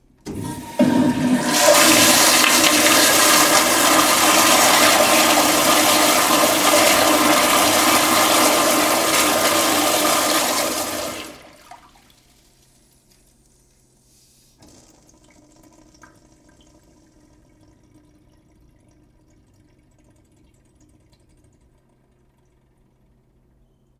马桶冲水 " 小便池，多重，远距离，清洁
标签： 小便池 厕所 厕所 冲洗 冲水器 小便器 厕所 浴室 冲水 马桶 冲洗 WC 浴室 卫生间
声道立体声